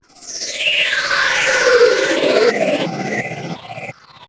ECHO SWASH.wav